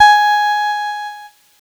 Cheese Note 14-G#3.wav